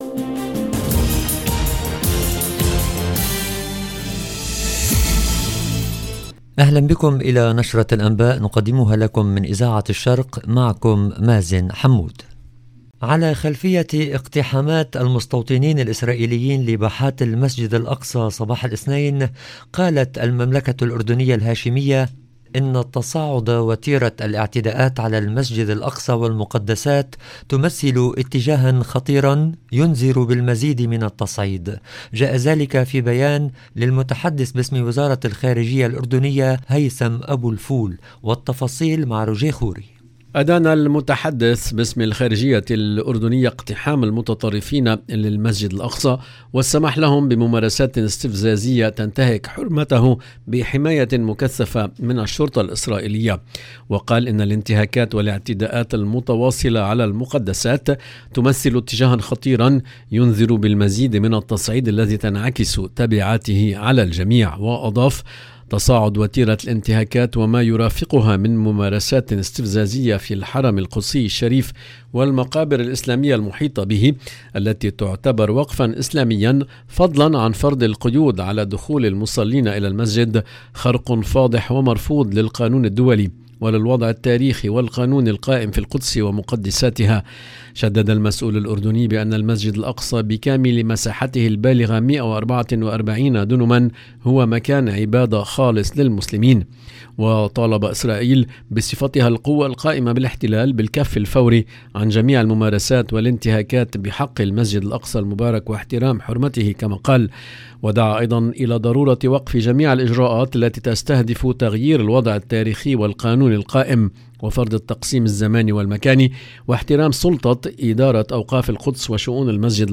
LE JOURNAL DU SOIR EN LANGUE ARABE DU 26/09/22